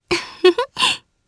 Selene-Vox_Happy1_jp.wav